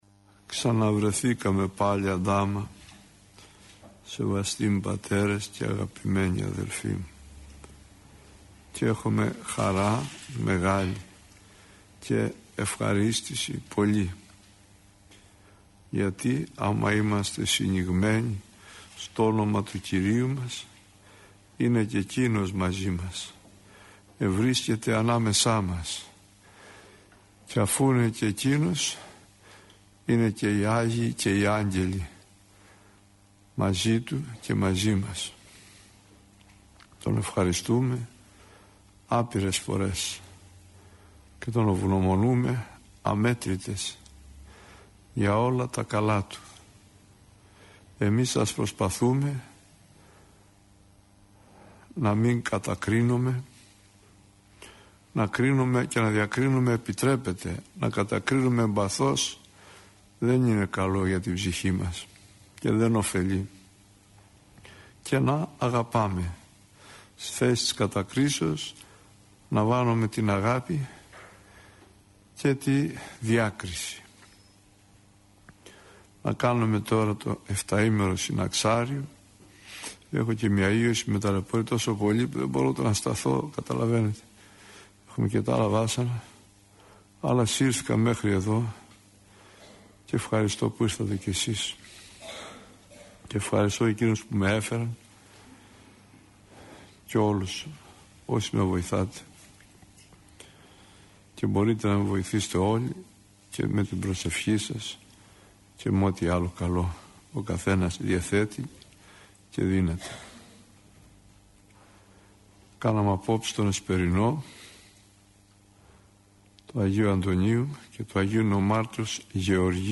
ηχογραφημένη ομιλία
Η εν λόγω ομιλία αναμεταδόθηκε από τον ραδιοσταθμό της Πειραϊκής Εκκλησίας.